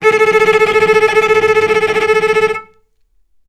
vc_trm-A4-mf.aif